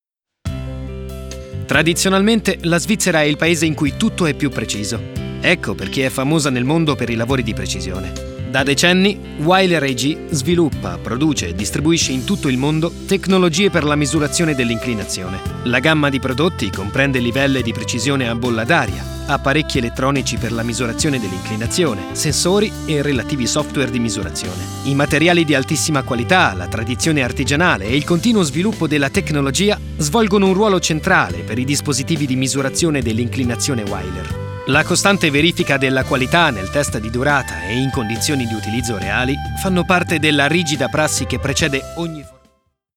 Ho una voce calda e giovane, utilizzabile in ogni tipo di progetto.
Sprechprobe: Industrie (Muttersprache):